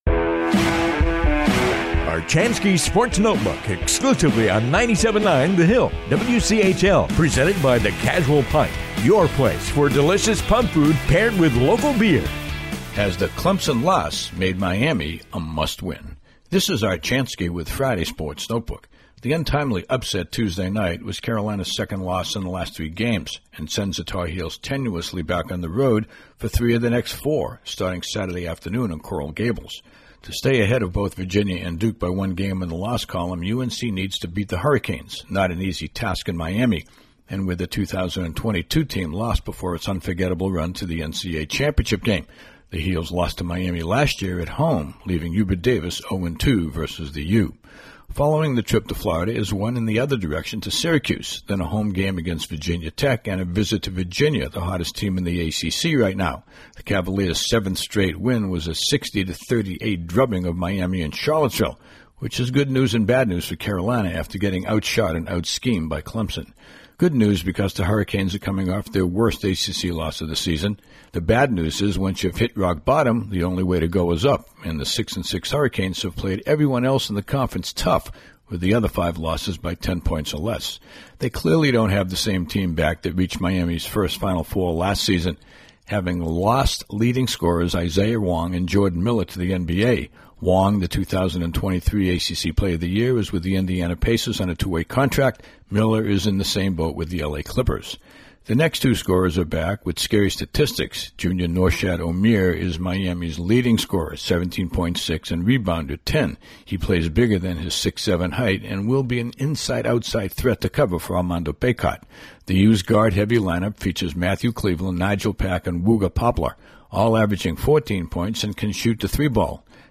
commentary airs daily on the 97.9 The Hill WCHL